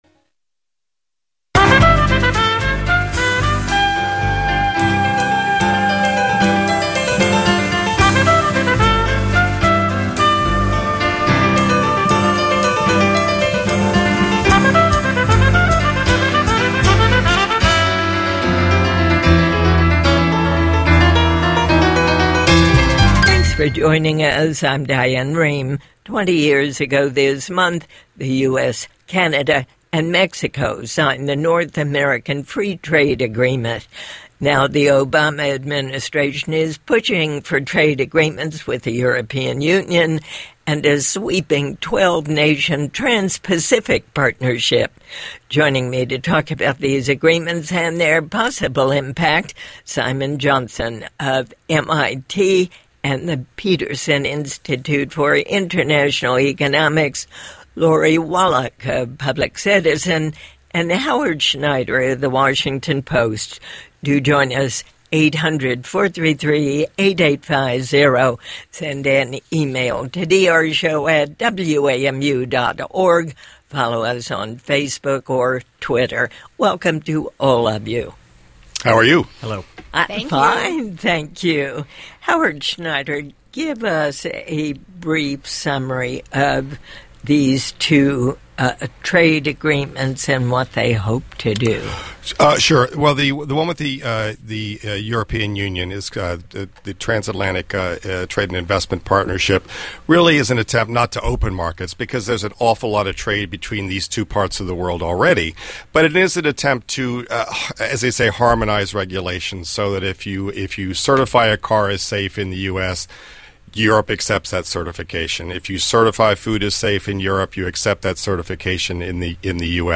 Diane and her guests discuss the pros and cons of two trade agreements in the works and lessons learned from NAFTA.